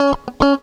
GTR 102 C#M.wav